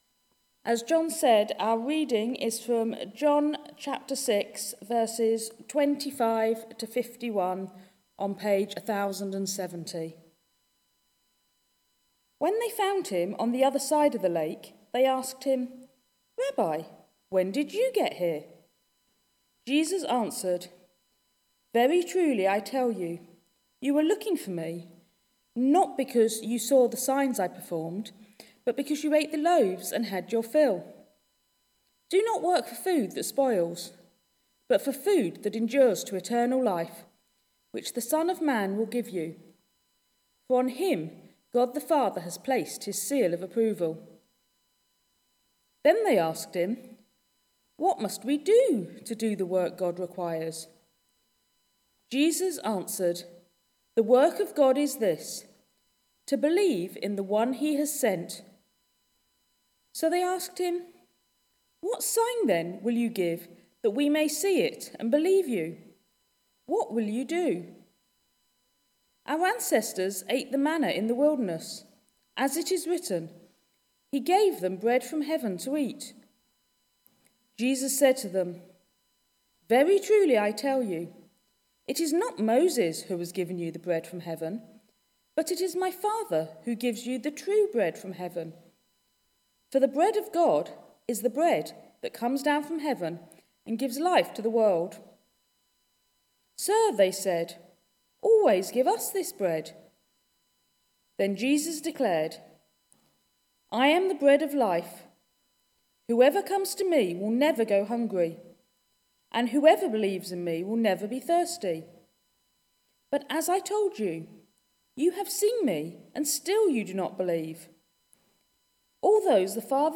Media Library We record sermons from our Morning Prayer, Holy Communion and Evening services, which are available to stream or download below.
Thirty Nine Articles Theme: Predestination (part 2) Sermon Search